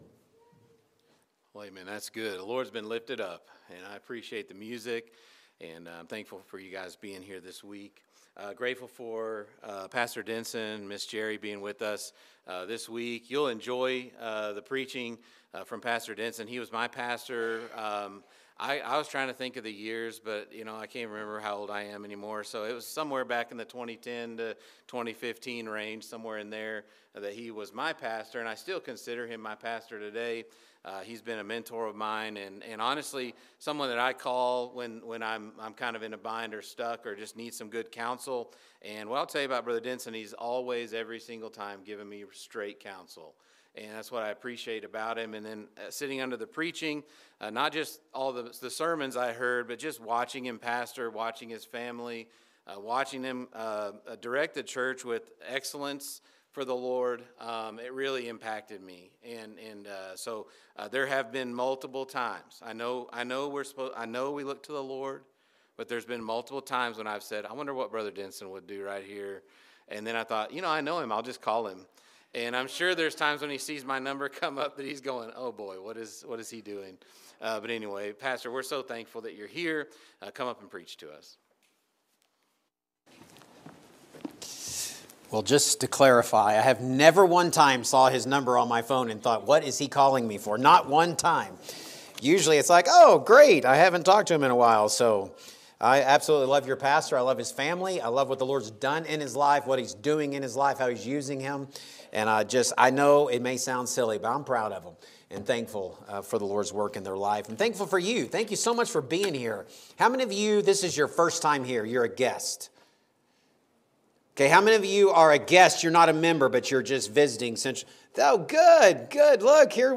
Spring Revival Sunday Morning